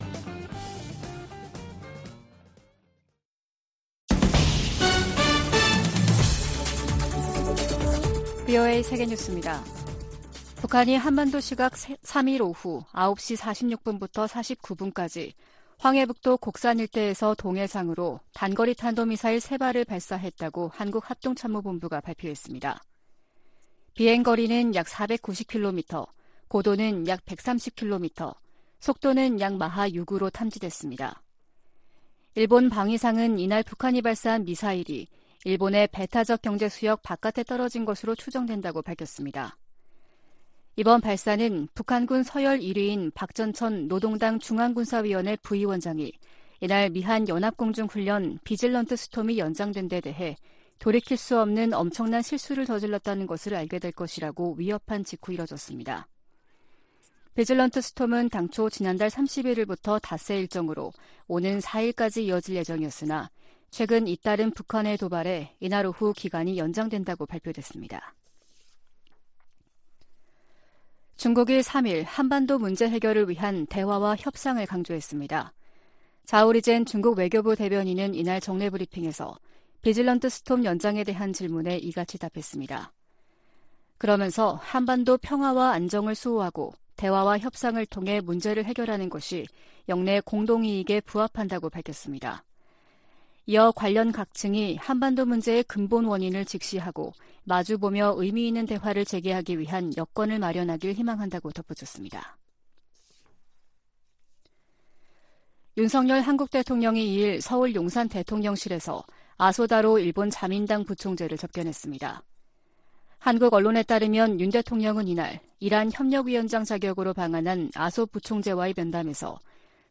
VOA 한국어 아침 뉴스 프로그램 '워싱턴 뉴스 광장' 2022년 11월 4일 방송입니다. 북한 김정은 정권이 어제 동해와 서해상에 미사일과 포탄을 무더기로 발사한 데 이어 오늘은 대륙간탄도미사일, ICBM을 쏘면서 도발 수위를 높였습니다. ICBM 발사는 실패한 것으로 추정된 가운데 미국과 한국 정부는 확장 억제 실행력을 높이면서 북한의 어떠한 위협과 도발에도 연합방위태세를 더욱 굳건히 할 것을 거듭 확인했습니다.